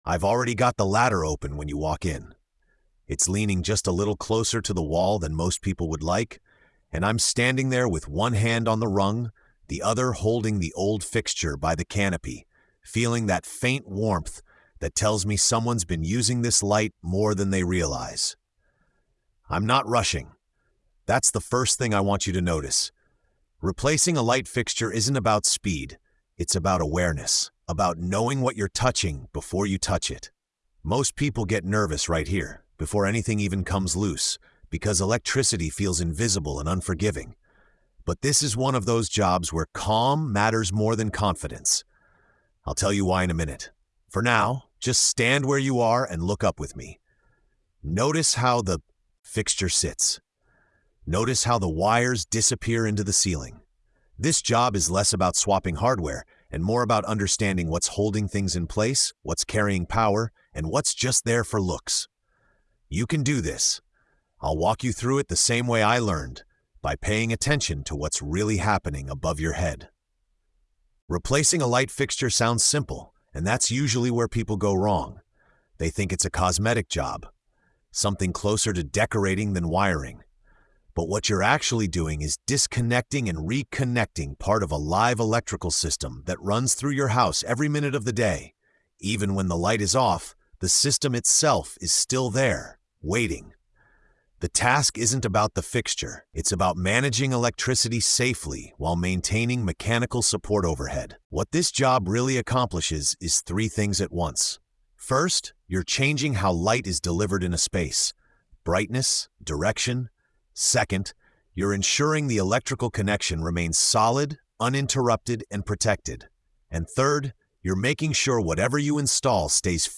In this episode of TORQUE & TAPE, a seasoned tradesman walks you through the quiet tension of replacing a ceiling light fixture—one of the most common home projects that people underestimate. Told entirely from the first-person perspective, the episode drops the listener directly beneath the ceiling, ladder open, hands steady, and power cut, revealing that this task is less about swapping hardware and more about judgment, patience, and respect for unseen systems. The tone is calm, grounded, and empowering, transforming anxiety around electricity into confidence through lived experience.